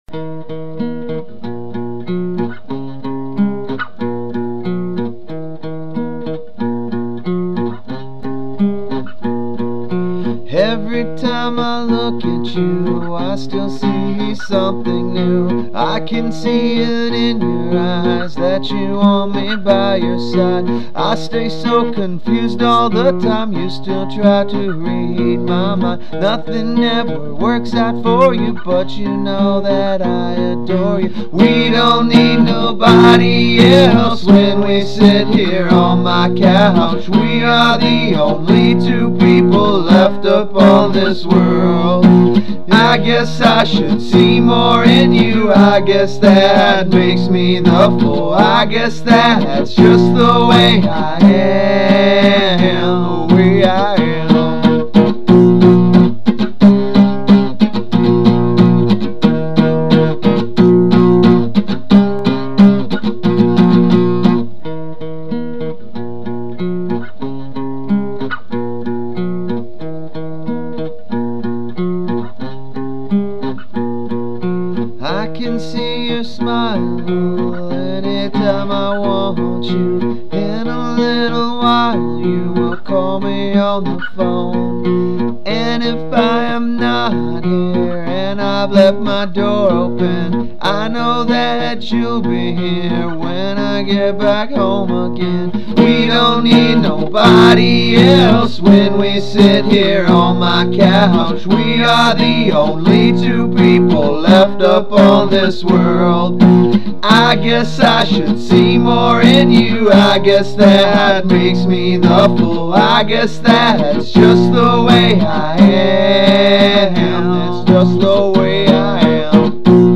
These Are The Purest Forms Of These Songs!